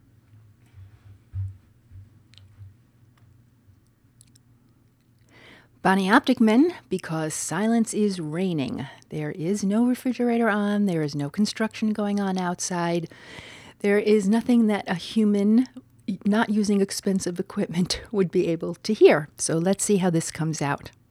Your natural recording environment demands something be done to reduce background noise.
You’re trying to record quality voice in the middle of a boiler factory.